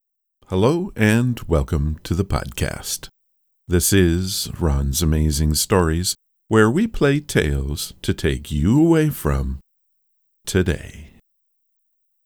The first is the raw recording. The only thing I did was to silence the breaks.